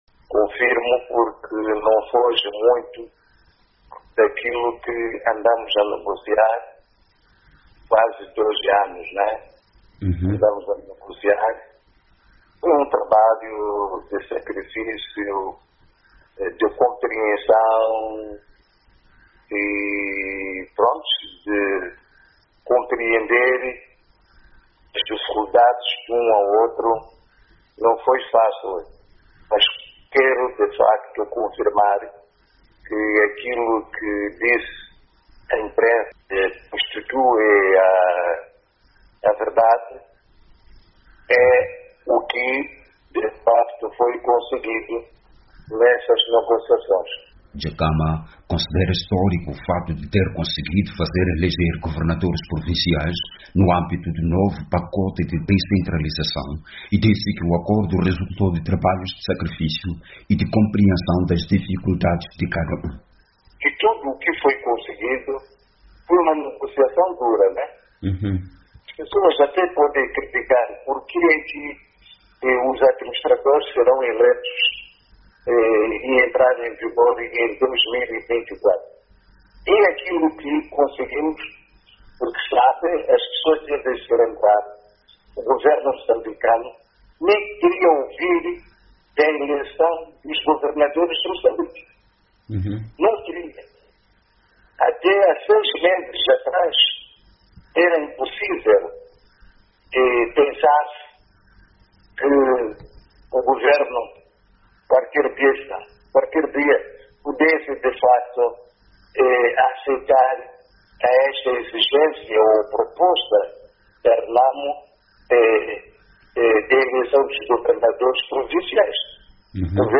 Em entrevista telefónica à VOA, Dhlakama considera histórico o facto de ter conseguido fazer eleger governadores provinciais no âmbito do novo pacote de descentralização e disse que o acordo resultou de trabalhos de sacrifício e de compreensão das dificuldades de cada um.